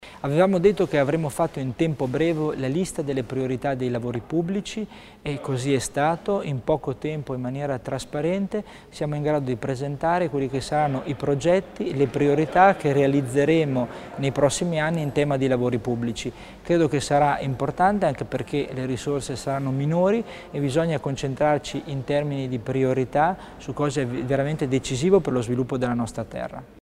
L'Assessore Tommasini spiega i principali interventi in tema di opere pubbliche